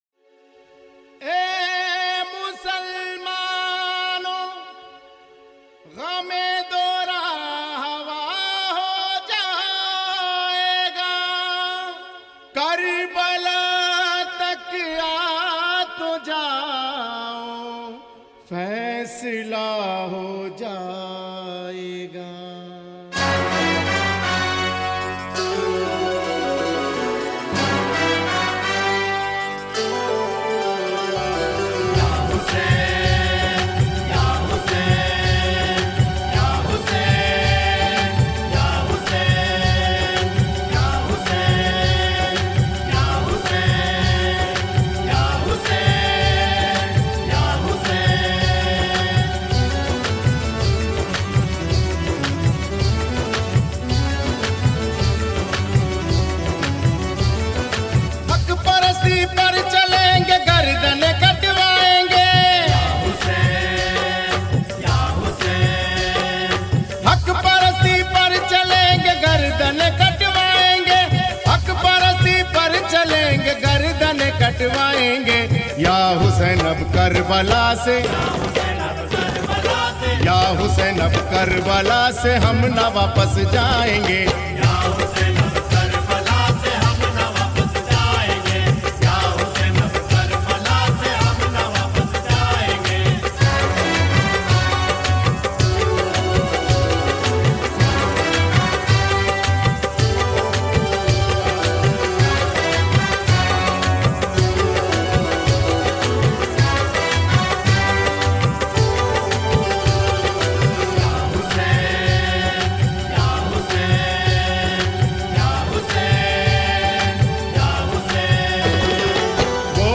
New Qawwali